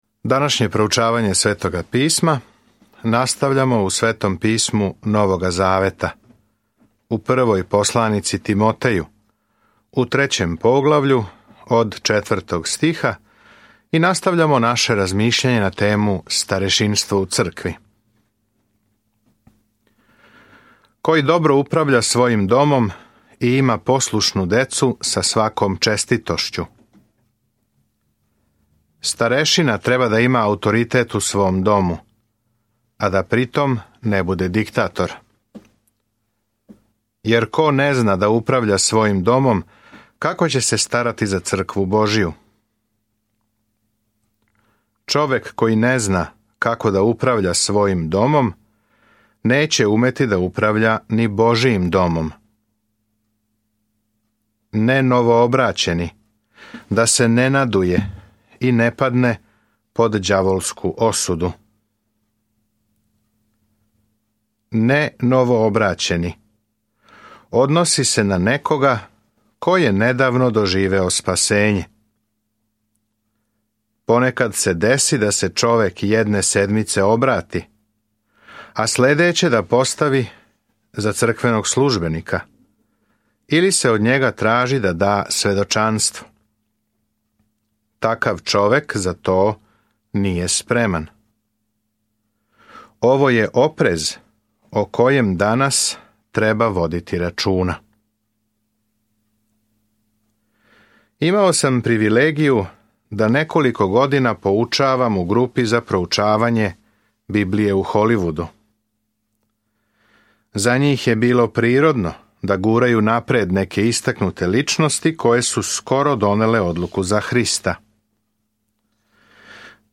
Timoteju 3:4-13 Dan 8 Započni ovaj plan Dan 10 O ovom planu Прво писмо Тимотеју пружа практичне индикације да је неко промењен јеванђеоским знацима побожности. Свакодневно путујте кроз 1. Тимотеју док слушате аудио студију и читате одабране стихове из Божје речи.